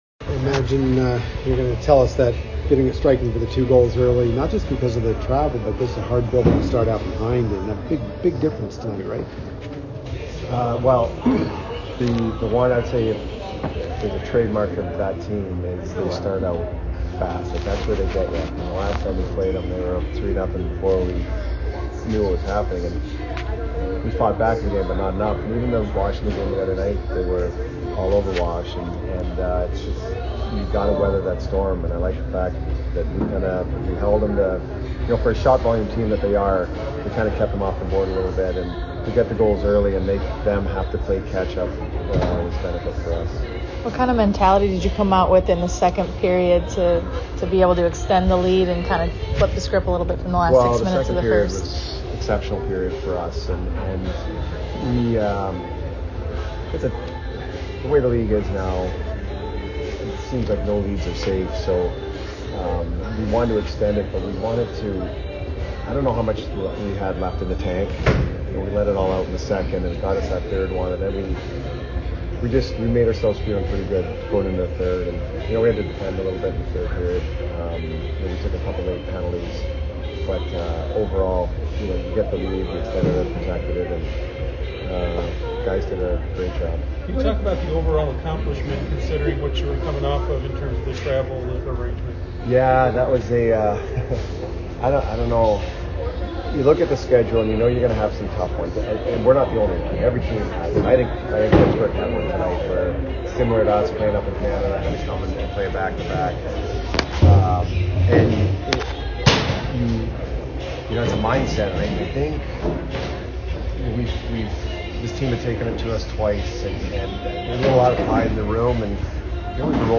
Jon Cooper Post - Game At Carolina Jan. 5, 2020